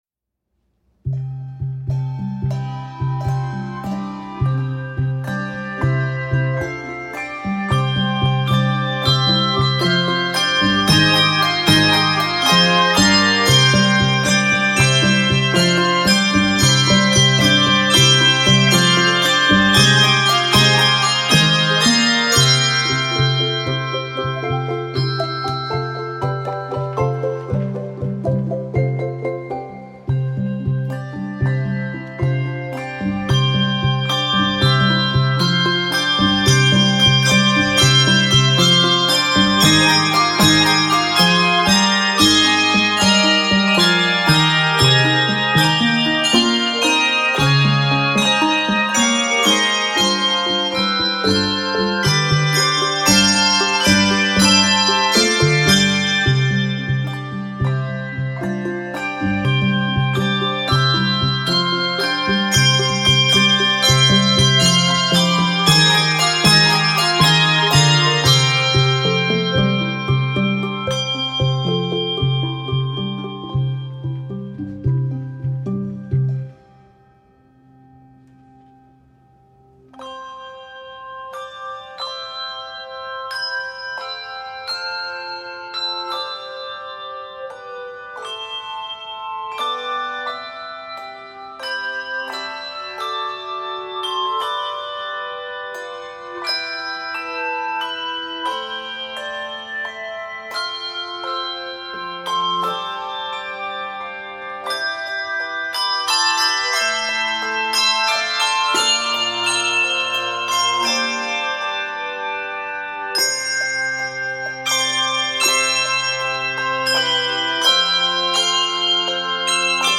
Key of C major, with numerous accidentals and meter changes.